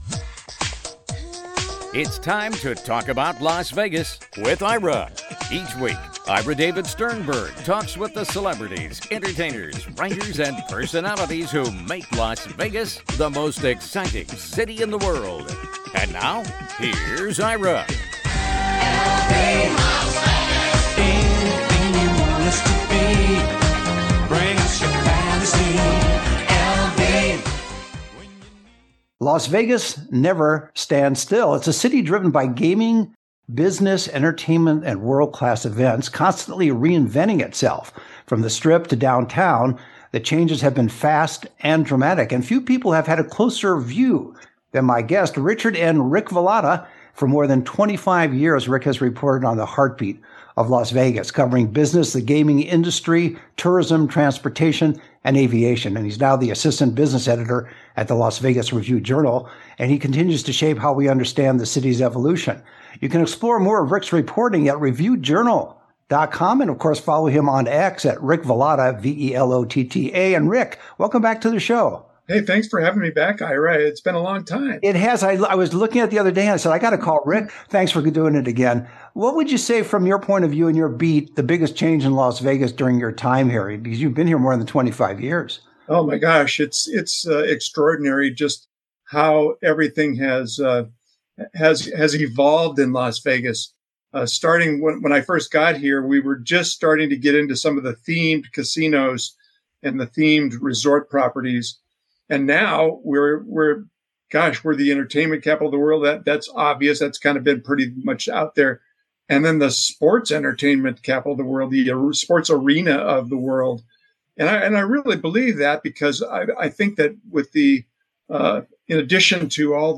talks with the celebrities, entertainers, writers, and personalities who make Las Vegas the most exciting city in the world!